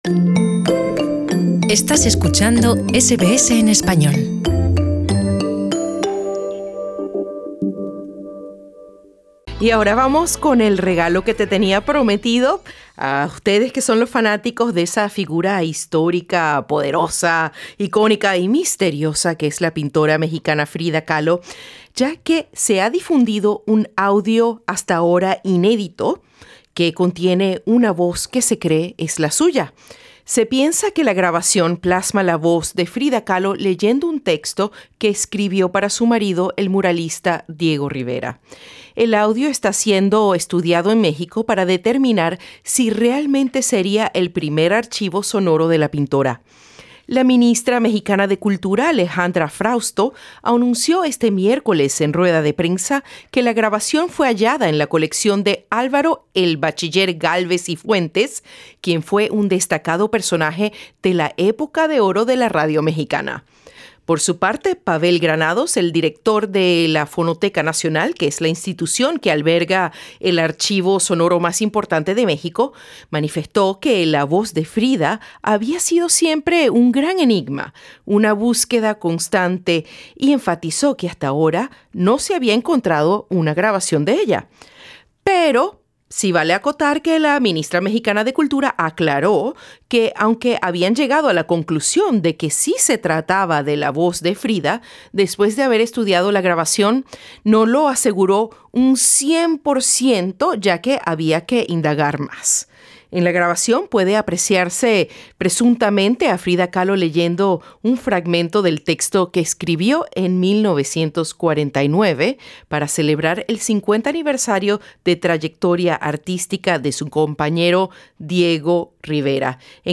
Un audio inédito, que contiene una voz que se cree es la de Frida Kahlo leyendo un texto que escribió para su marido, el muralista Diego Rivera, es estudiado en México para determinar si realmente sería el primer archivo sonoro de la pintora.
En el audio se escucha una voz melodiosa y femenina.
Con acordes de guitarra de fondo, en la grabación puede apreciarse presuntamente a Kahlo leyendo un fragmento del texto que escribió en 1949 para celebrar el 50 aniversario de trayectoria artística de Rivera.